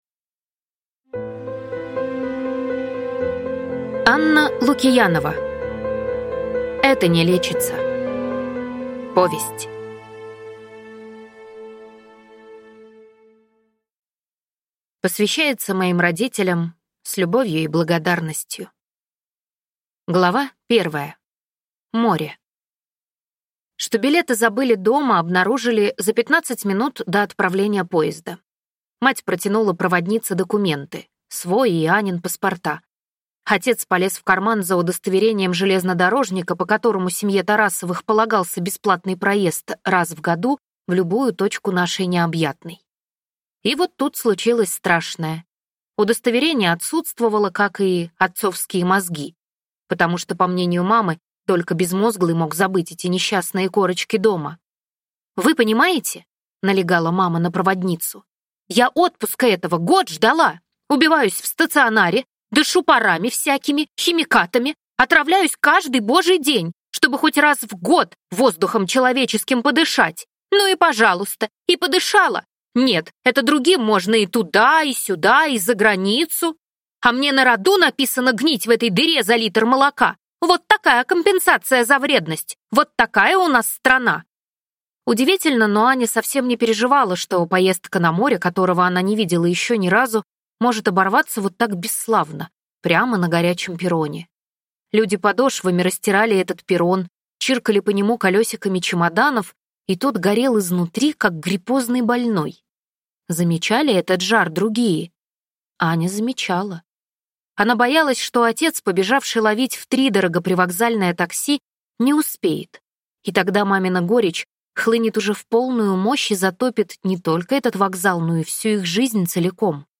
Аудиокнига Это не лечится | Библиотека аудиокниг